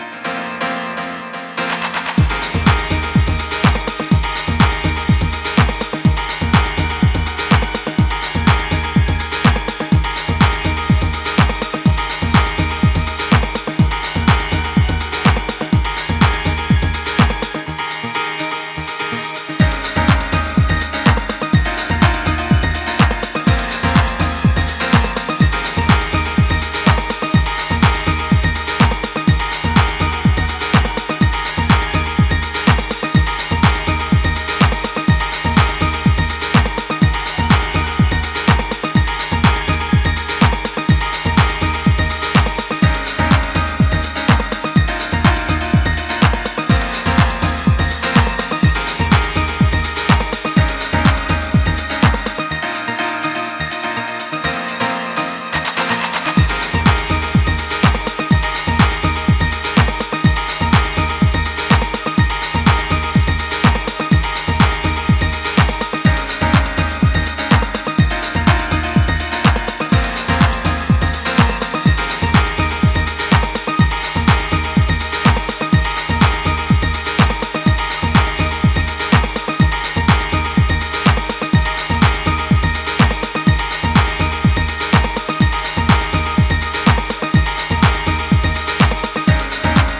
supplier of essential dance music
House